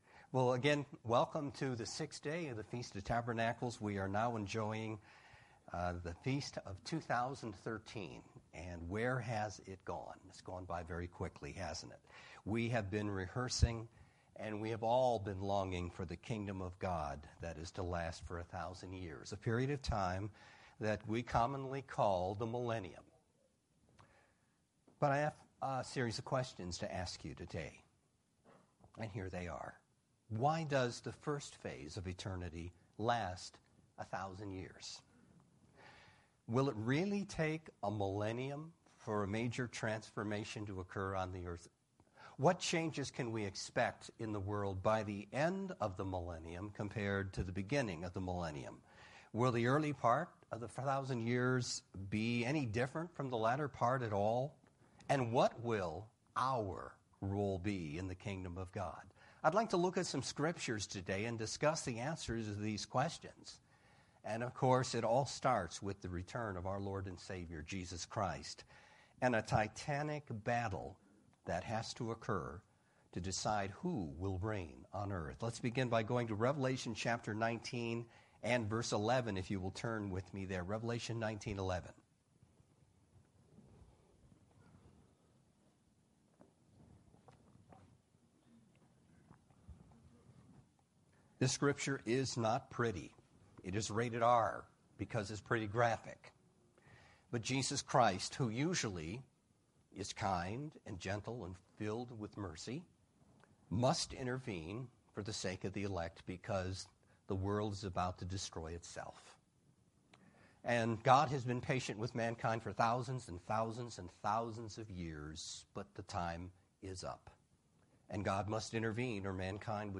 This sermon was given at the Ocean City, Maryland 2013 Feast site.